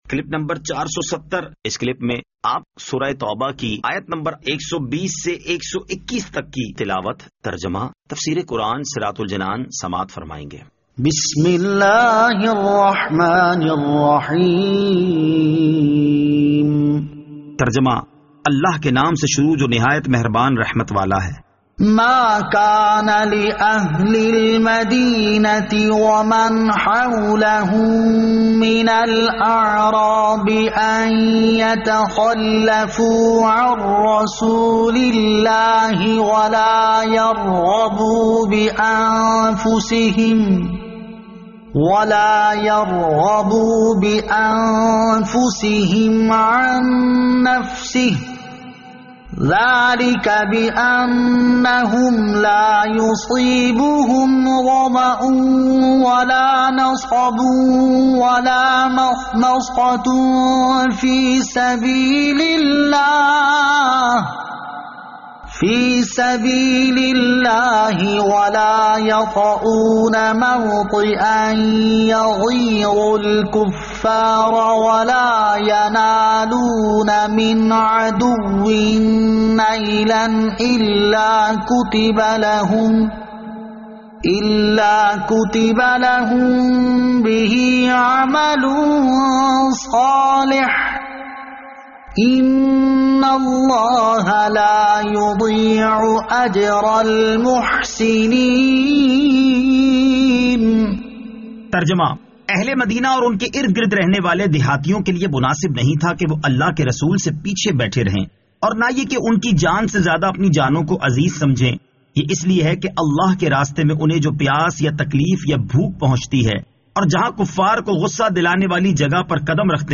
Surah At-Tawbah Ayat 120 To 121 Tilawat , Tarjama , Tafseer